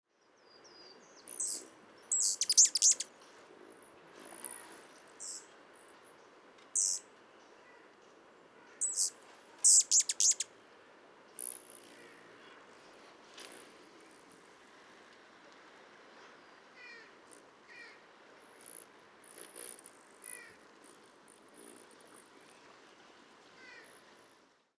Rufous Hummingbird